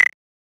edm-perc-22.wav